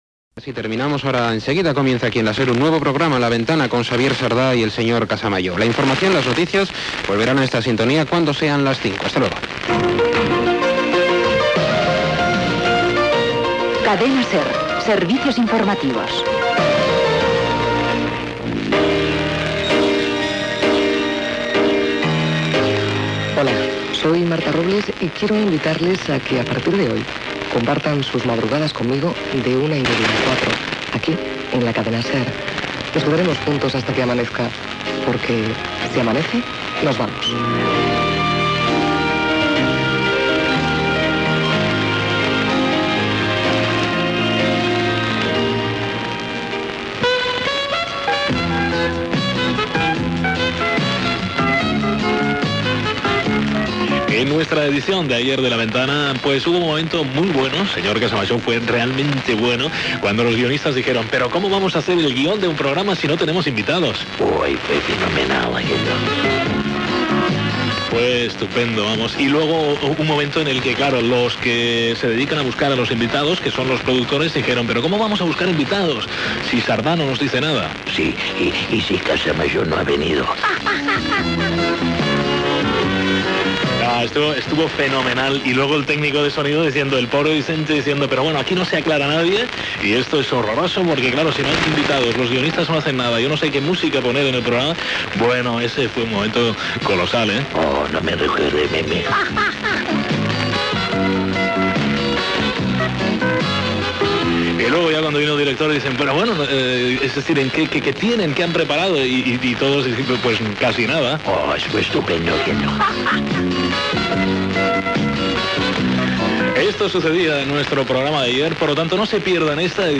Final dels serveis informatius, promoció de "Si amanece nos vamos" (veu Marta Robles), presentació del primer programa.
Entreteniment